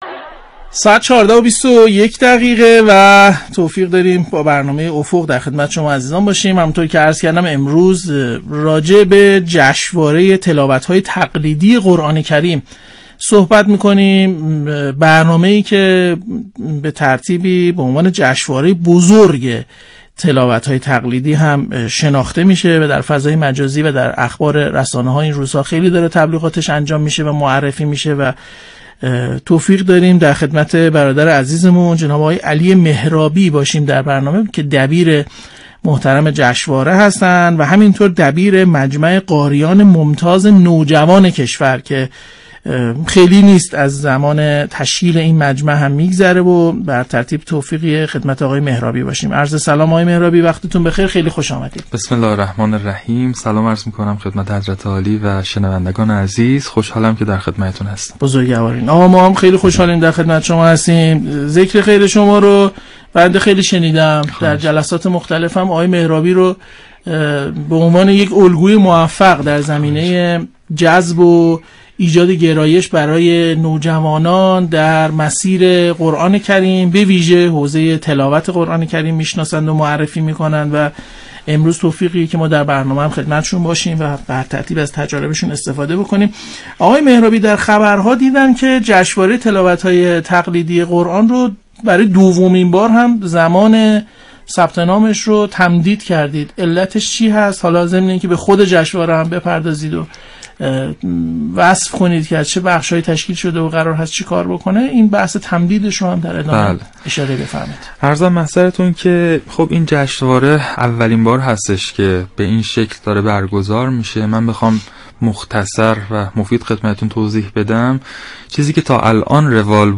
روی امواج رادیو قرآن رفت
روی خط تلفنی برنامه آمد